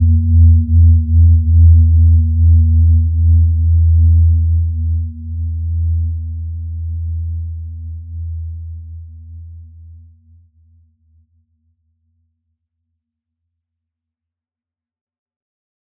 Gentle-Metallic-3-E2-mf.wav